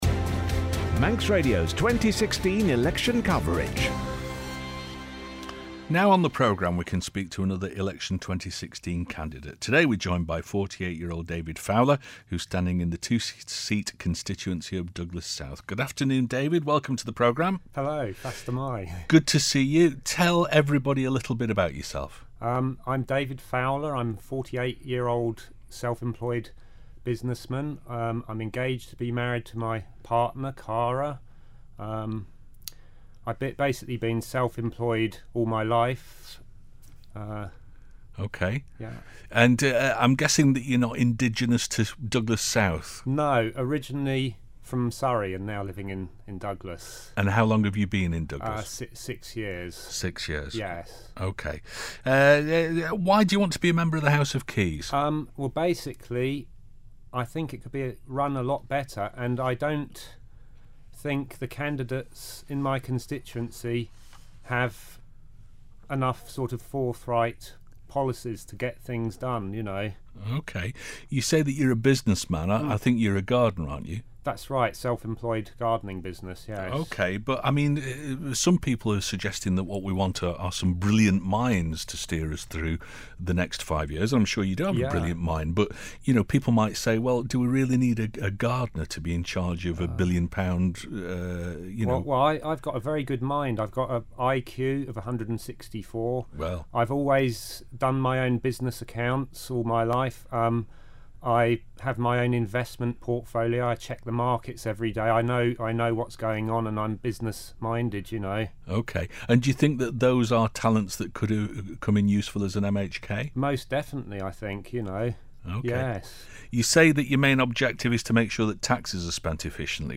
Election 2016 constituency debate for Douglas South